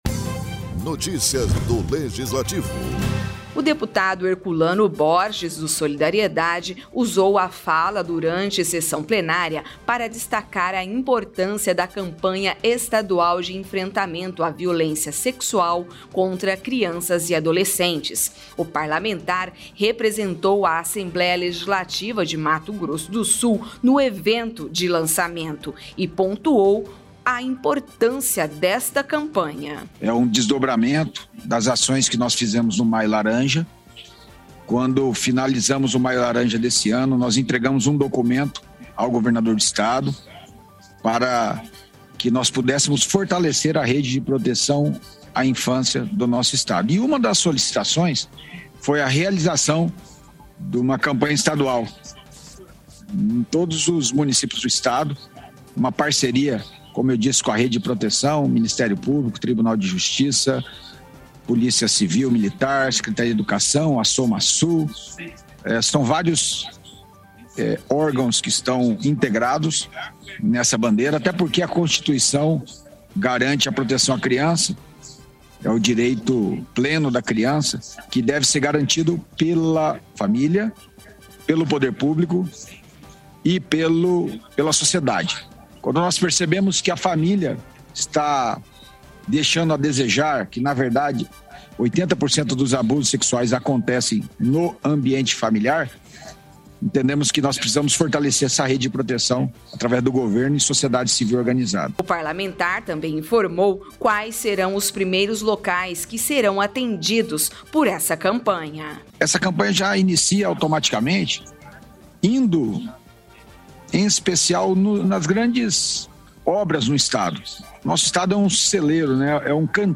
O deputado Herculano Borges (Solidariedade) usou a fala durante a sessão plenária, para destacar a importância da Campanha Estadual de Enfrentamento à Violência Sexual contra Crianças e Adolescentes.